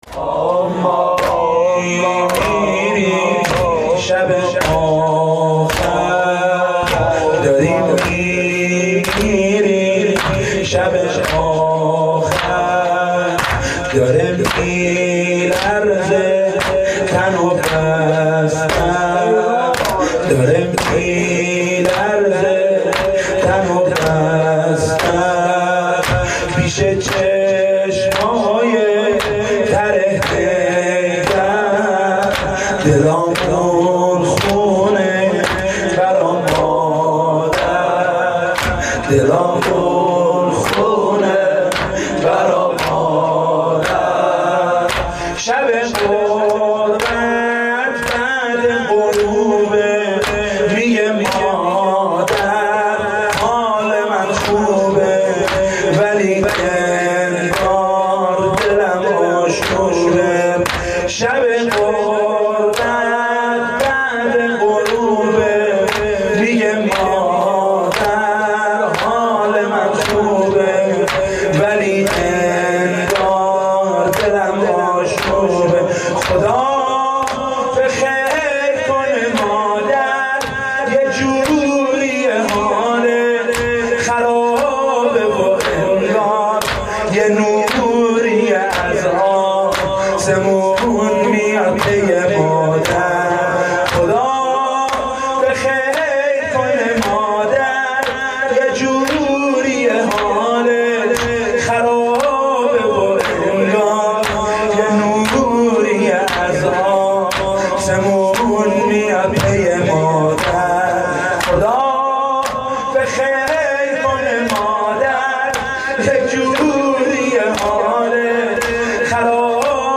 نوای فاطمیه
مداحی فاطمیه